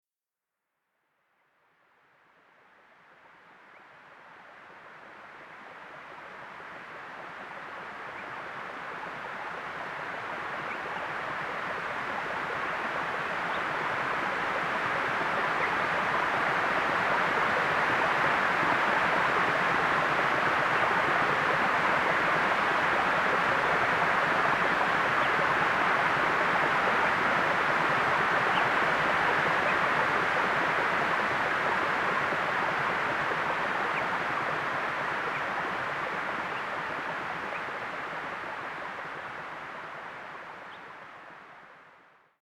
SONAR Home Studio was used during mix down for reverb and delay effects.
As it's name implies the simple sounds of nature brought to you by analog synthesis. Realized on the MFOS Modular.
Stream and birds.mp3